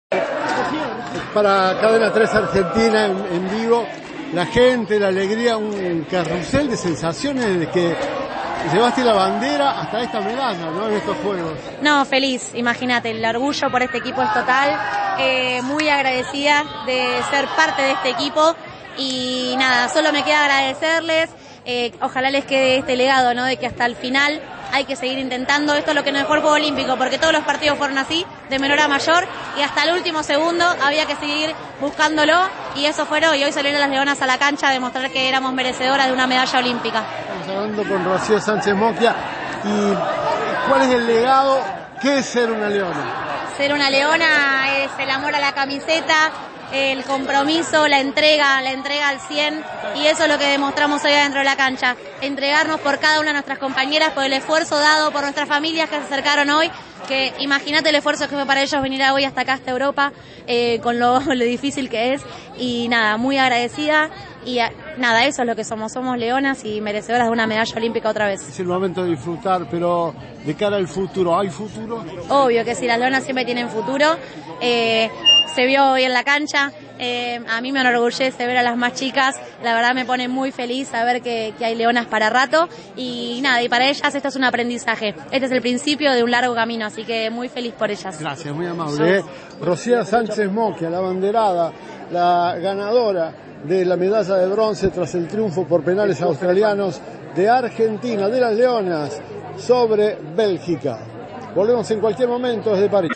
Las jugadoras de Las Leonas hablaron con Cadena 3 luego del triunfo ante Bélgica en los Juegos Olímpicos.